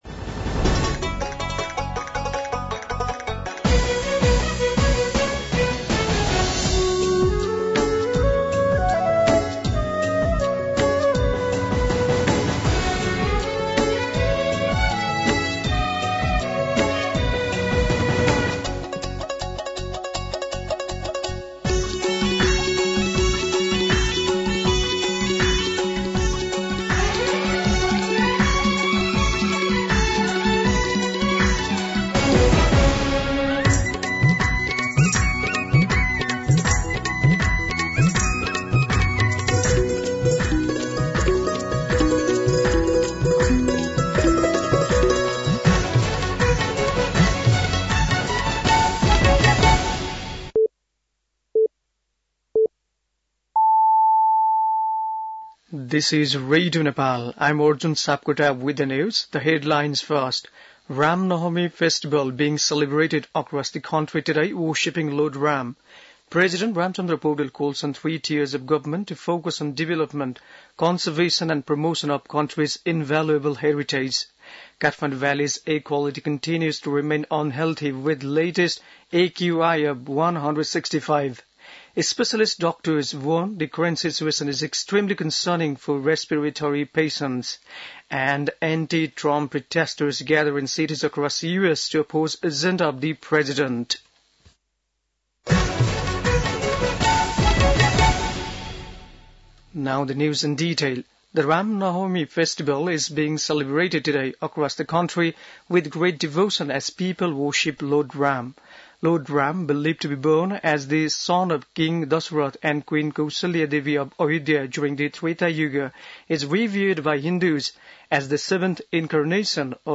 दिउँसो २ बजेको अङ्ग्रेजी समाचार : २४ चैत , २०८१
2-pm-English-News-2.mp3